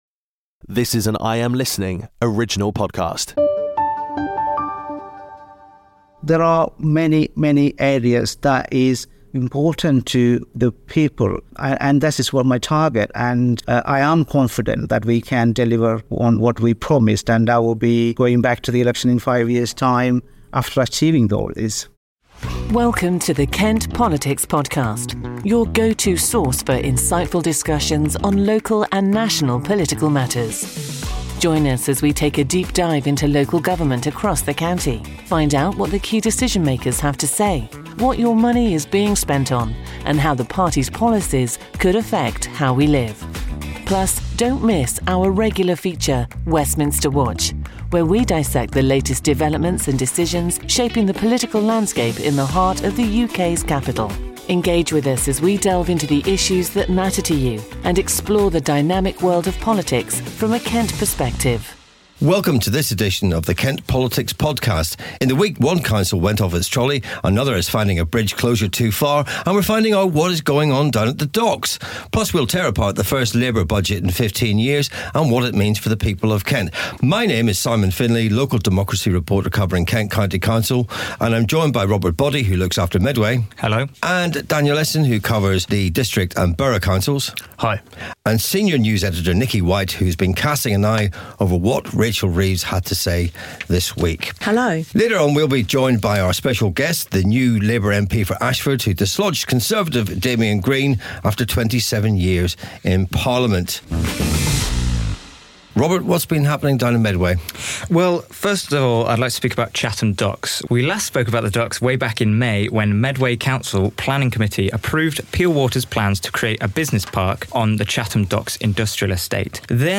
Spotlight Interview with Sojan Joseph: Discusses his journey from Kerala to becoming Ashford’s new Labour MP after 27 years of Conservative representation by Damian Green.